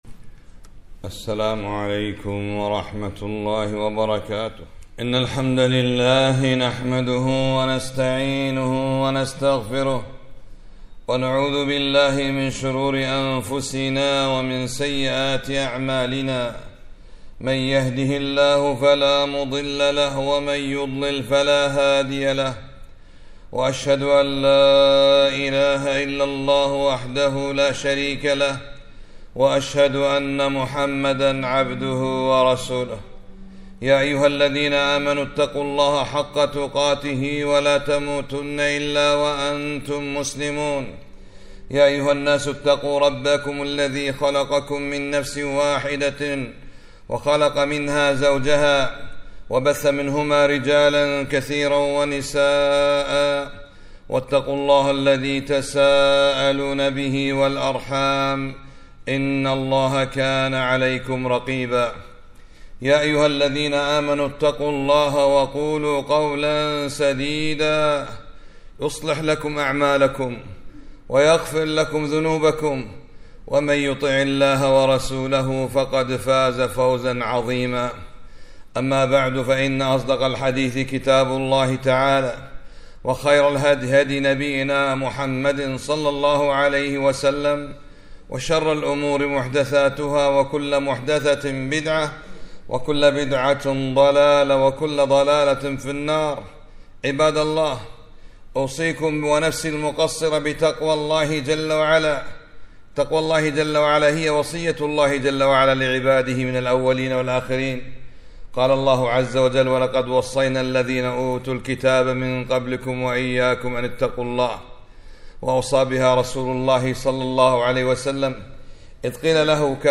خطبة - لا يدعو الوالد على ولده